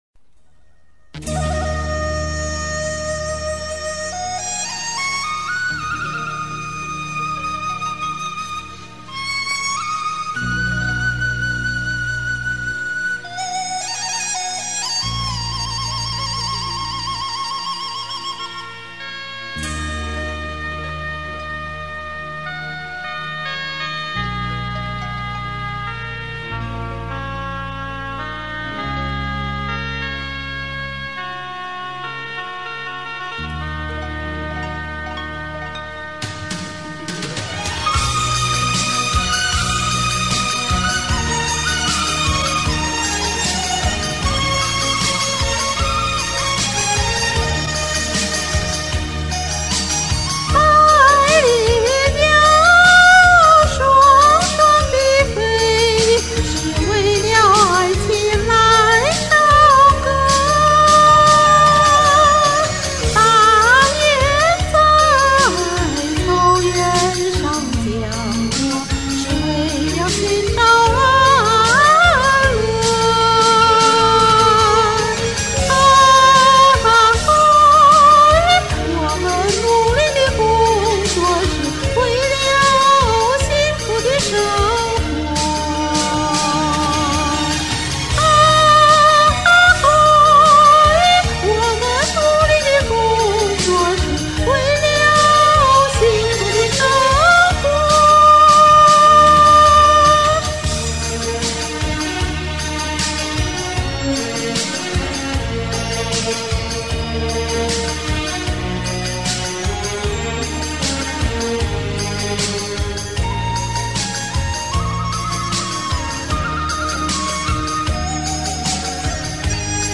反正也沒歌可貼，就把這吼了一遍的拋出來湊數，拋磚引玉。